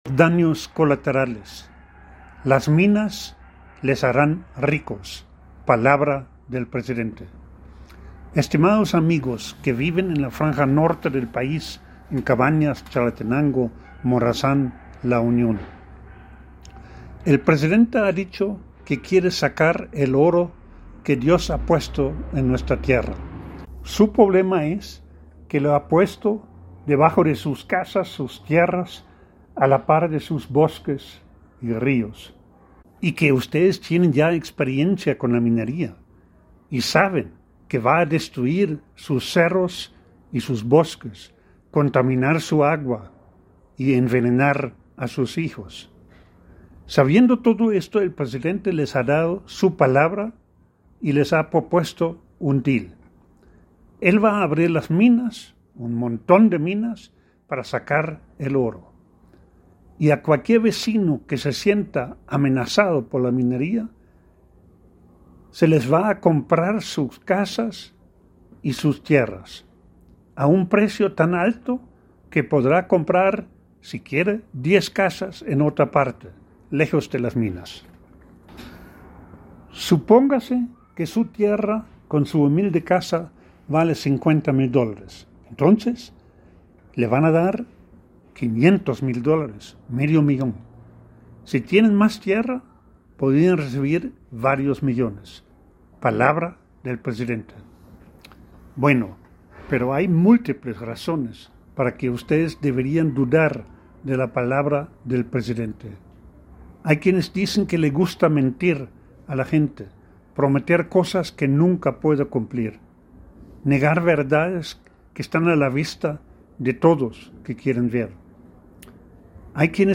El audio en la voz del autor: